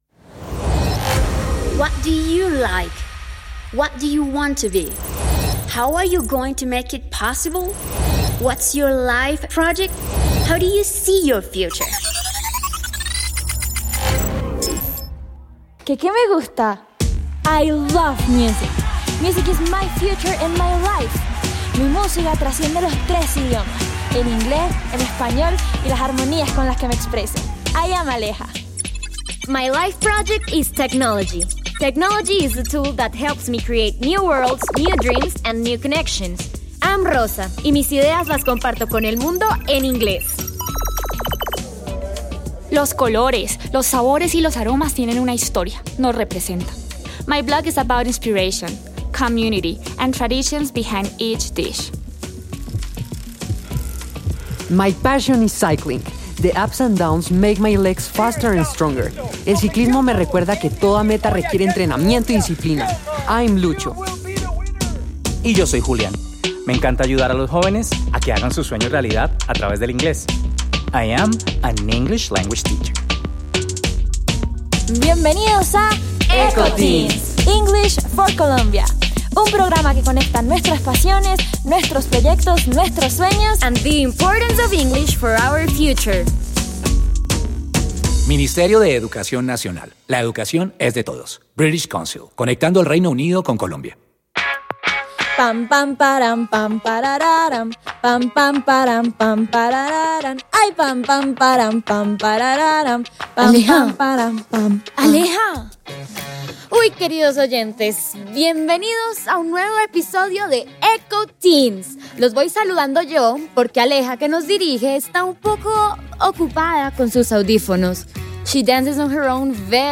Ir a mis descargas Eco Teens Makes You Wanna Dance programa radial